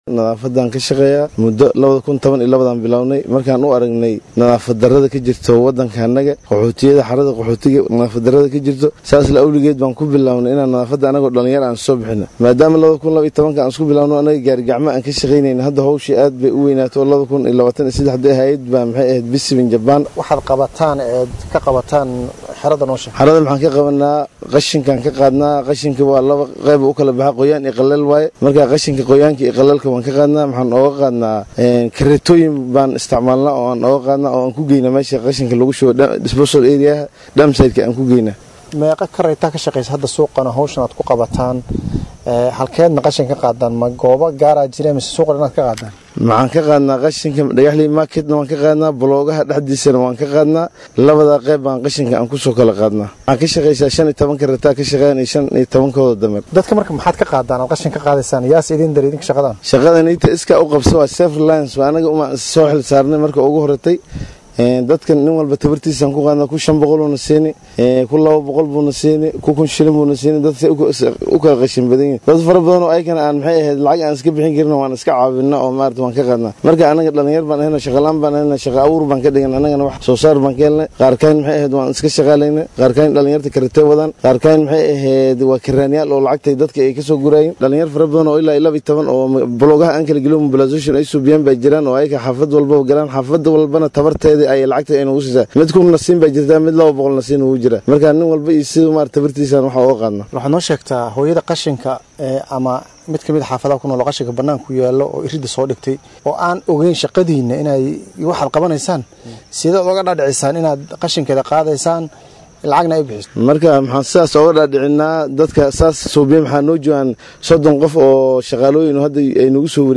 Wareysi-Dadaab.mp3